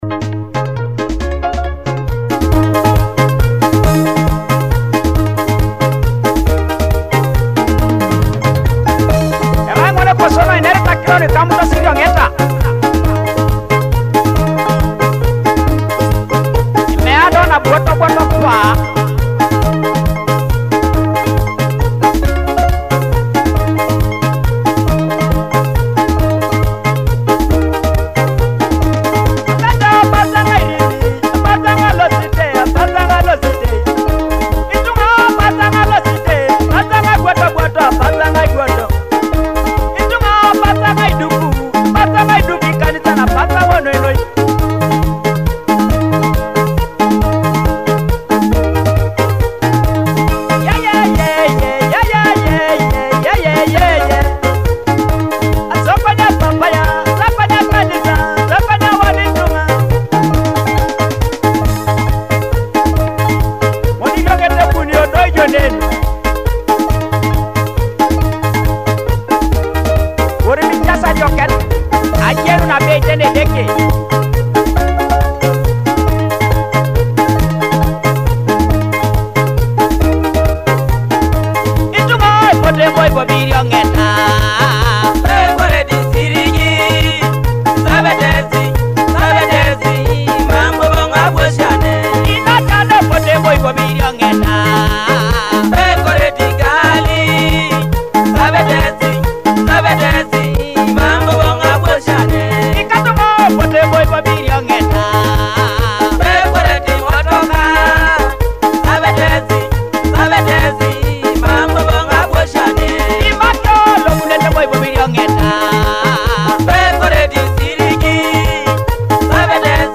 featuring Teso cultural rhythms